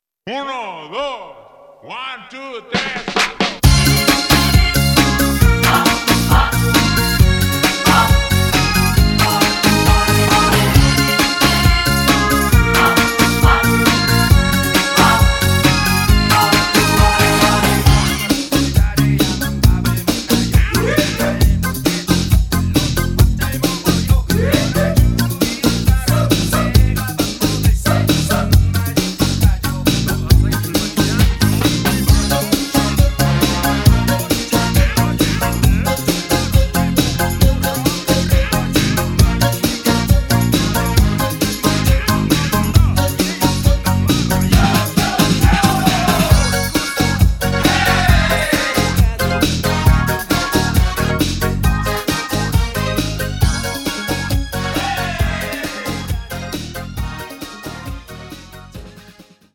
음정 -1키 3:13
장르 가요 구분 Voice MR